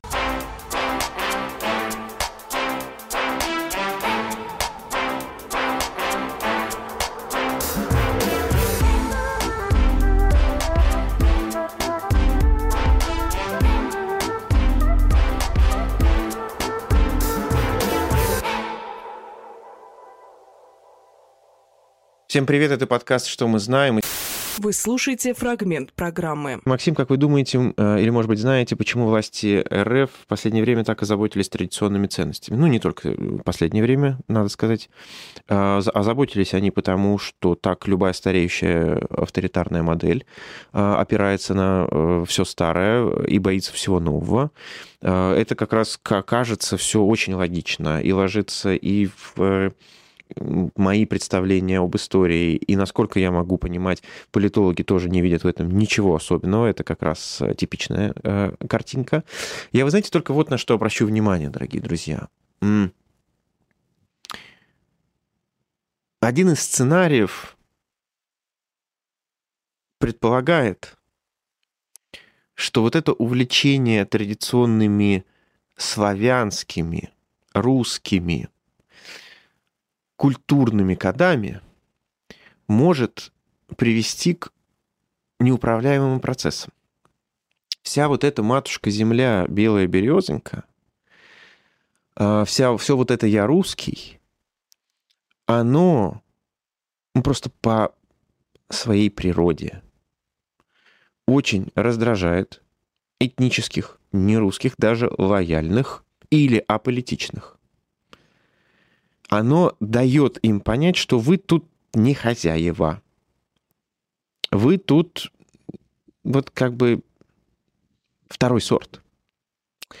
Максим Курниковглавный редактор «Эха», журналист
Фрагмент эфира от 08.02.26